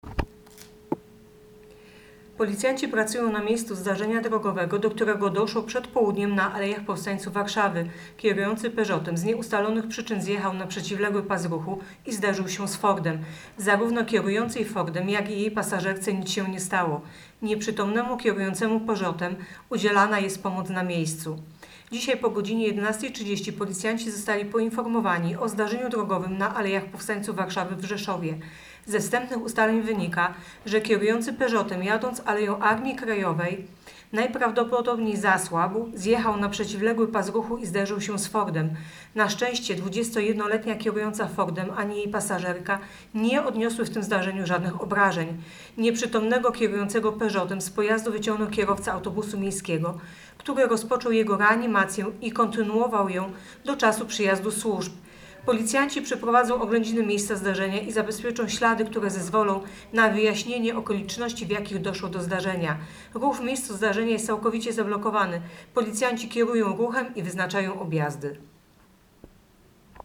Opis nagrania: Nagranie informacji pt. Zdarzenie drogowe na al. Powstańców Warszawy.